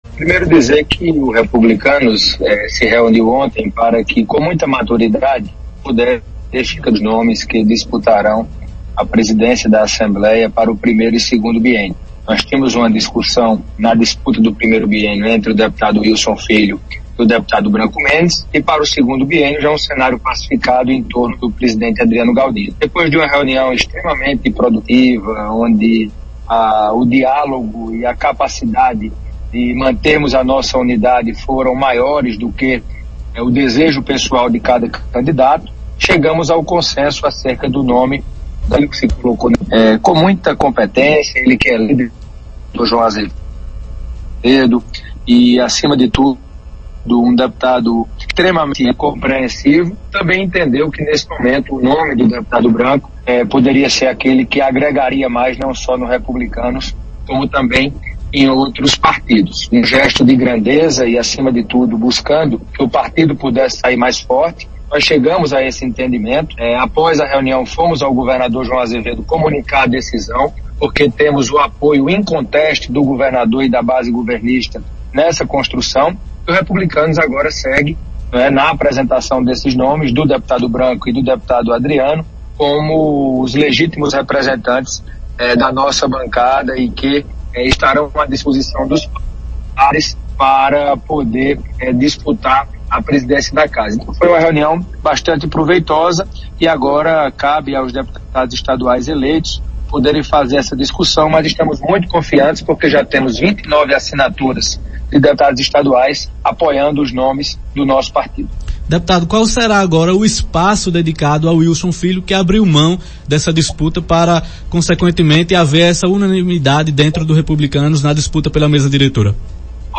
As declarações repercutiram no programa Arapuan Verdade.